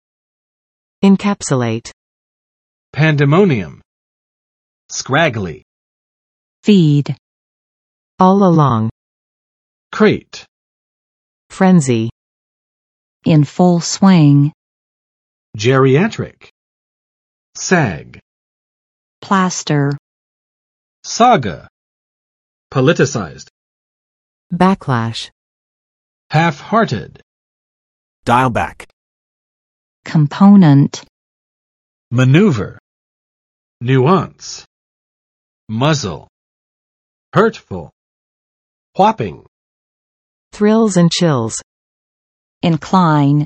[ɪnˋkæpsə͵let] v. 扼要表述；压缩；概括; 将……装入胶囊；将……封进内部
encapsulate.mp3